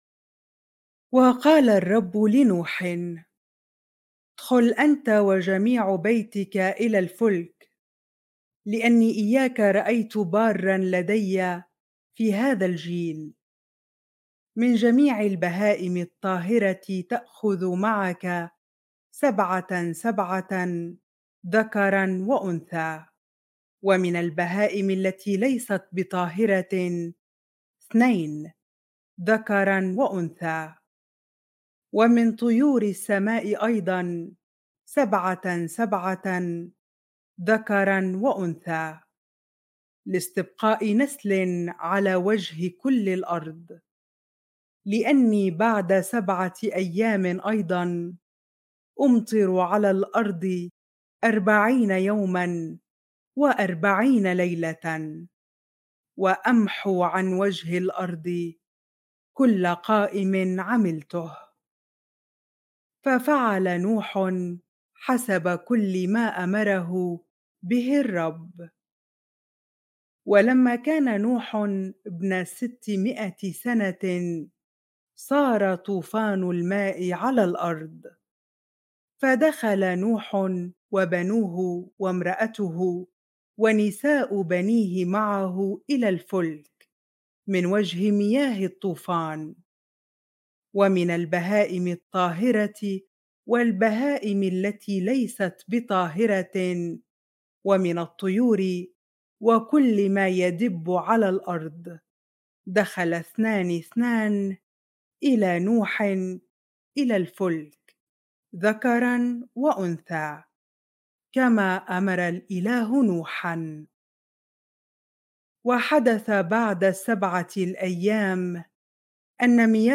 bible-reading-genesis 7 ar